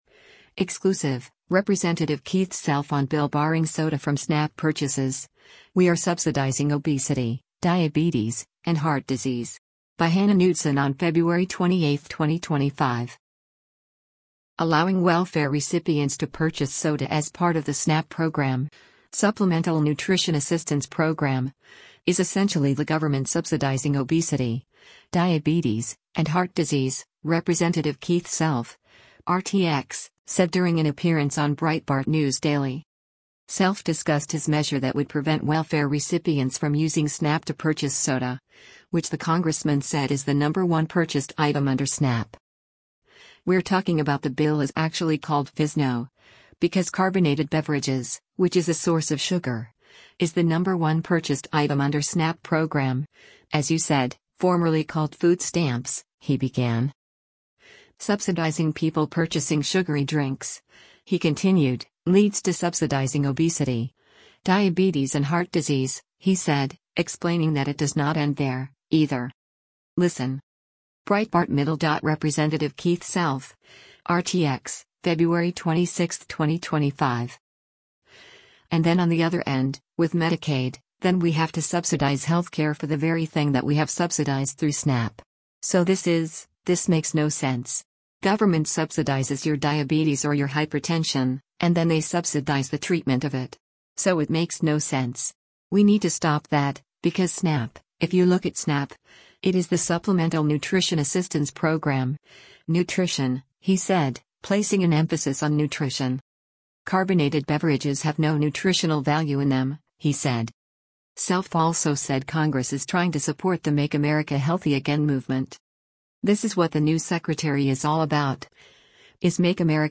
Allowing welfare recipients to purchase soda as part of the SNAP program (Supplemental Nutrition Assistance Program) is essentially the government subsidizing obesity, diabetes, and heart disease, Rep. Keith Self (R-TX) said during an appearance on Breitbart News Daily.
“And then on the other end, with Medicaid, then we have to subsidize health care for the very thing that we have subsidized through SNAP. So this is — this makes no sense. Government subsidizes your diabetes or your hypertension, and then they subsidize the treatment of it. So it makes no sense. We need to stop that, because SNAP, if you look at SNAP, it is the Supplemental Nutrition Assistance Program — nutrition,” he said, placing an emphasis on “nutrition.”